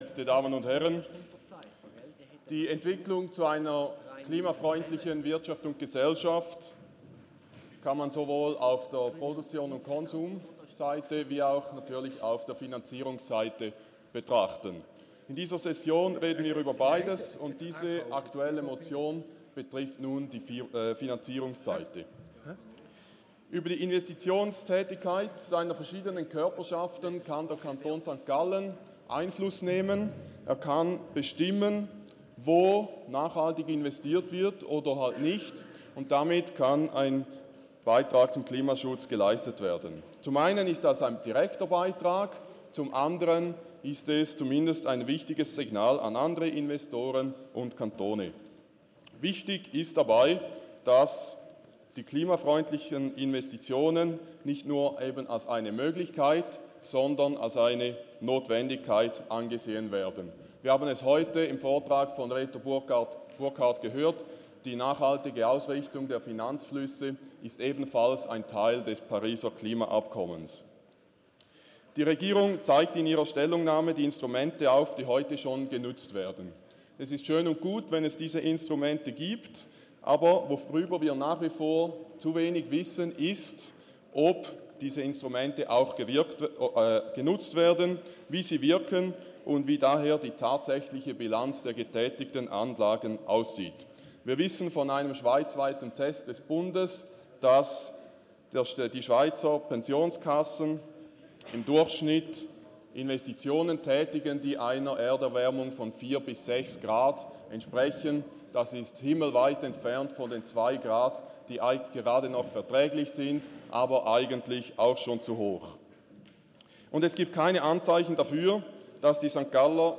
Session des Kantonsrates vom 11. bis 13. Juni 2019